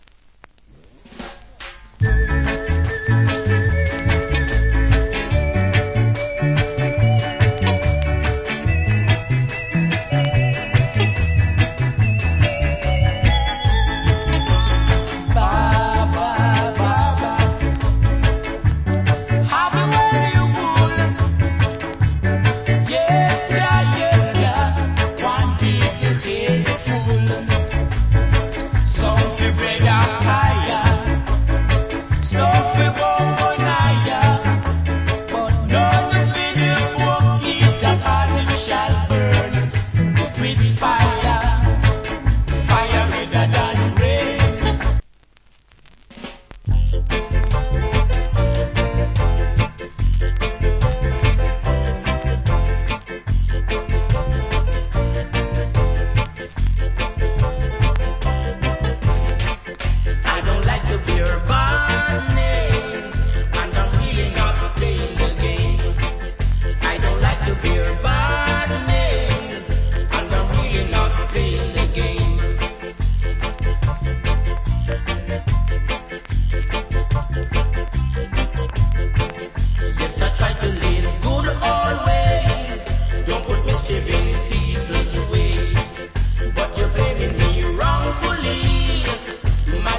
Nice Early Reggae Vocal.